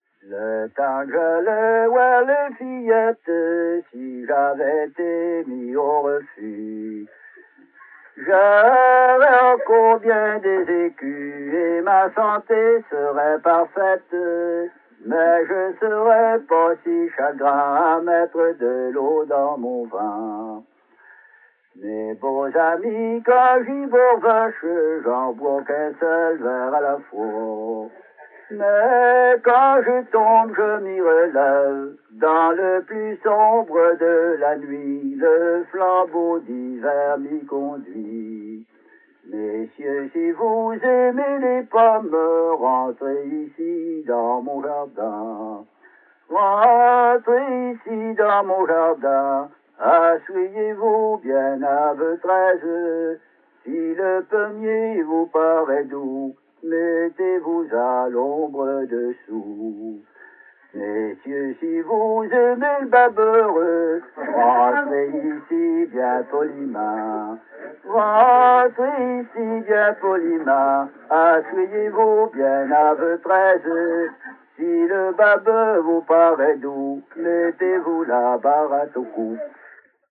Chanson strophique
Belle-Anse